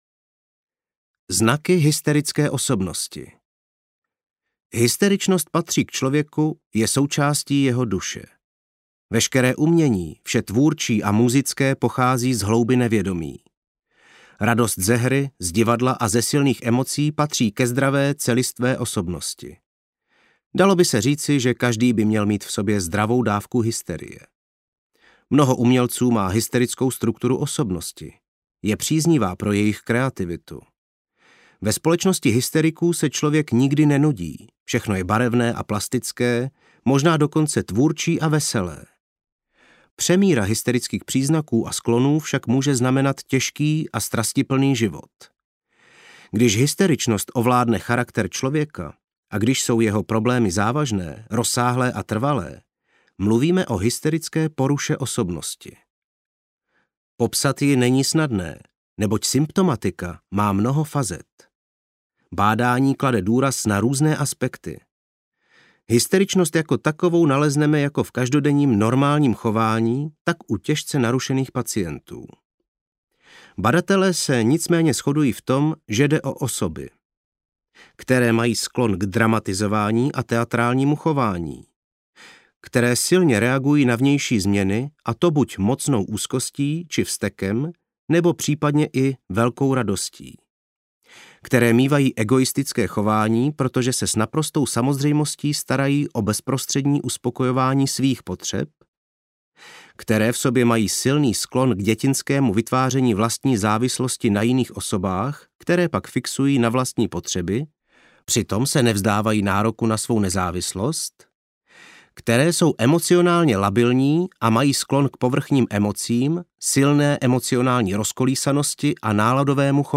Hysterie – strach z odmítnutí audiokniha
Ukázka z knihy
hysterie-strach-z-odmitnuti-audiokniha